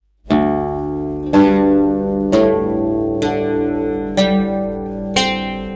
우드의 개방 현은 "D2 G2 A2 D3 G3 C4" 미국식 튜닝으로 연주된다.